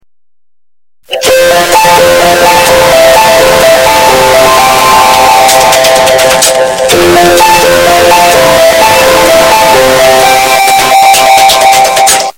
Memes
Distorted Naplan